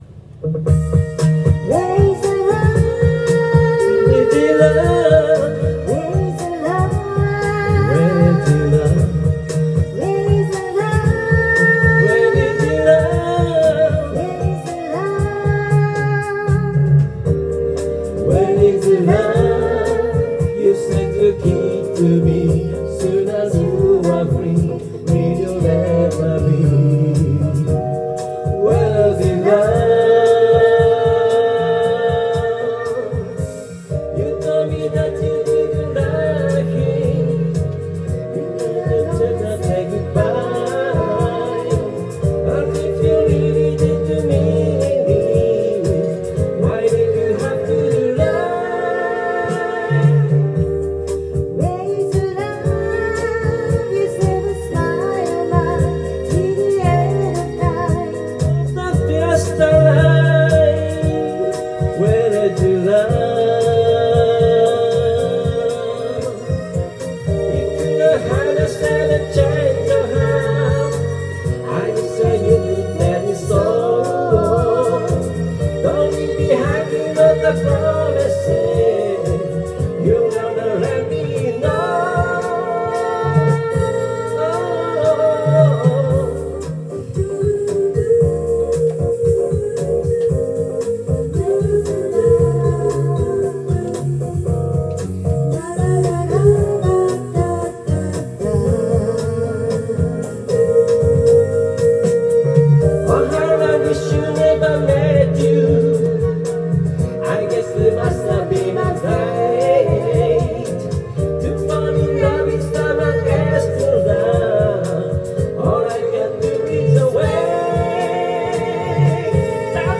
Duet & Chorus Night Vol. 22 TURN TABLE